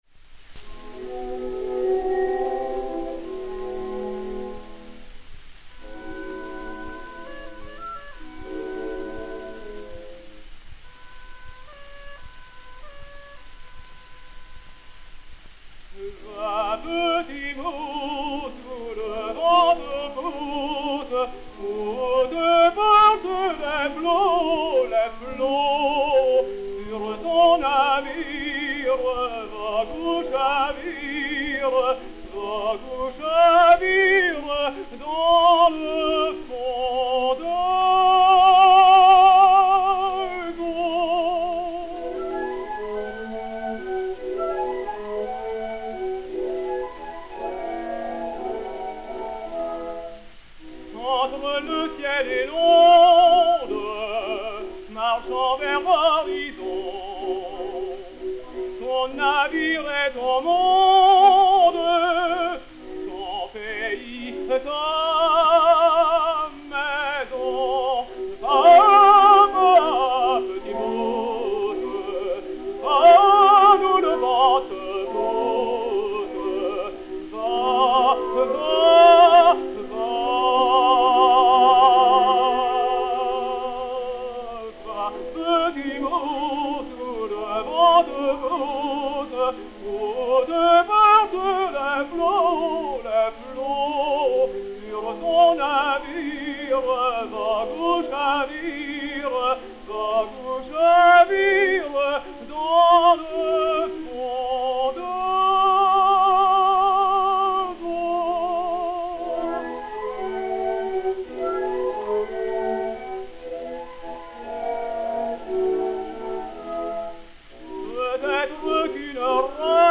voix de ténor
et Orchestre
Disque Pour Gramophone 032277, mat. 02643v, réédité sur Gramophone W 182, enr. à Paris le 24 décembre 1912